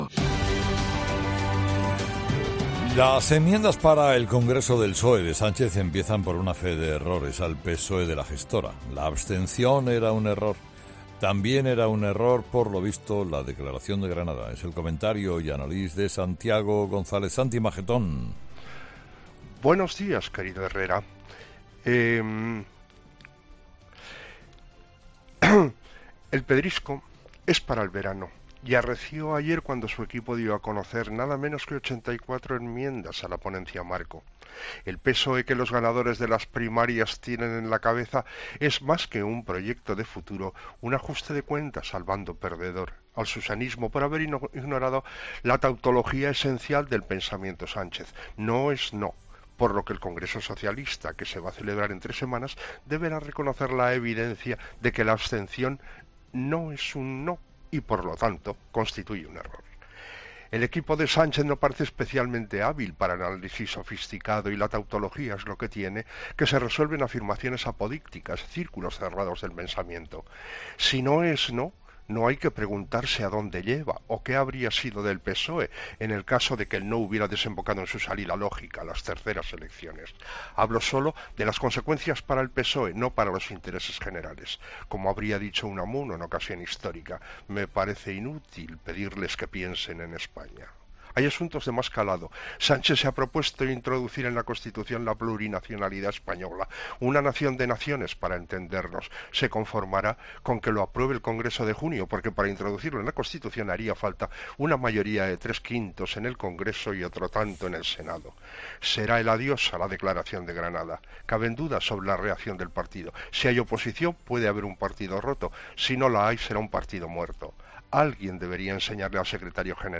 AUDIO: La plurinacionalidad de Sánchez, en el comentario de Santiago González en 'Herrera en COPE'.